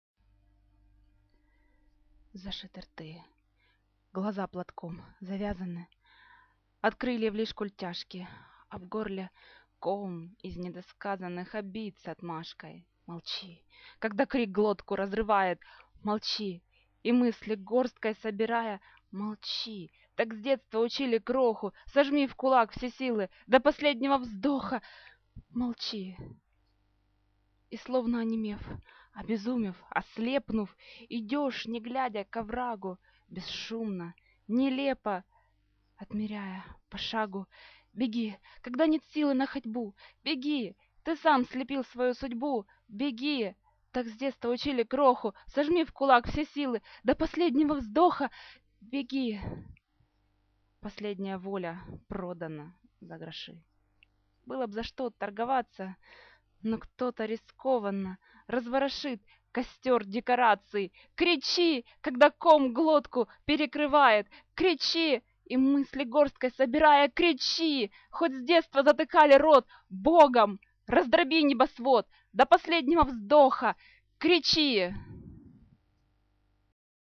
спасибо... да, вот я почувствовала, что надо его прочесть самой, потому что с такой нервной ритмикой с первого раза тяжело прочесть правильно. такие эмоции захлестнули под Tool "The Grudge"